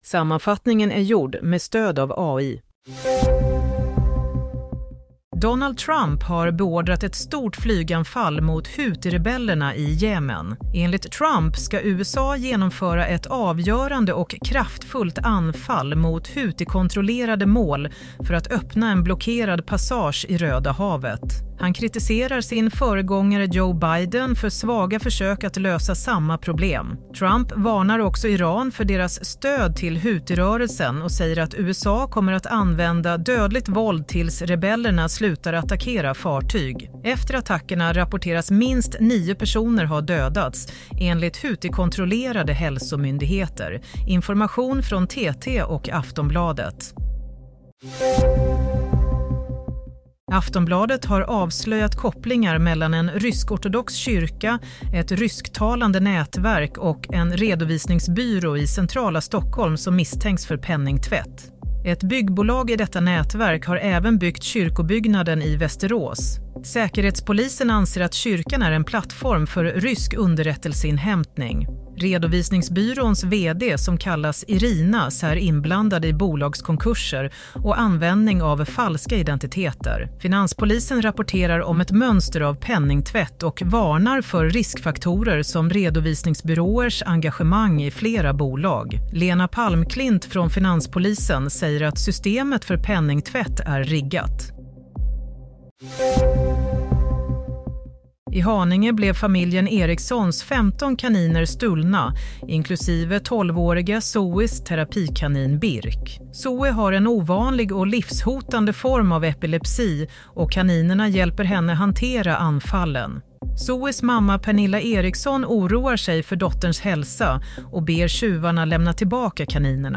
Nyhetssammanfattning - 15 mars 22:00